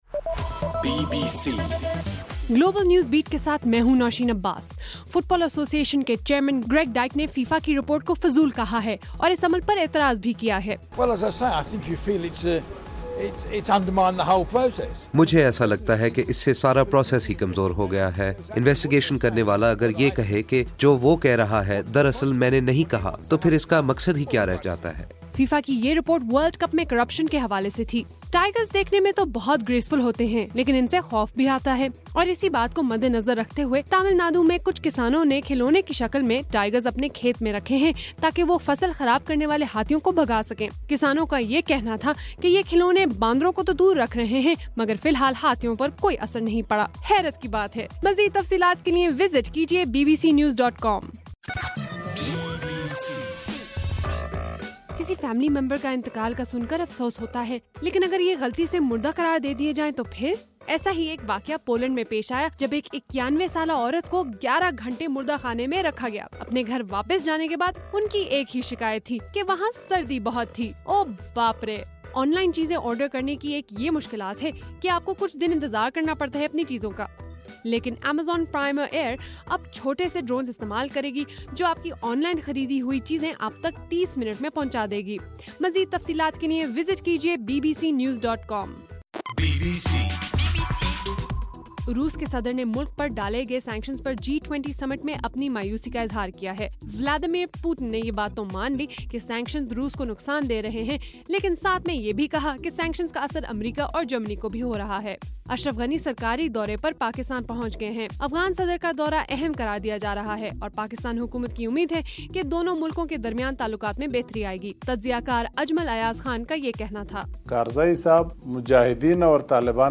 نومبر 14: رات 10 بجے کا گلوبل نیوز بیٹ بُلیٹن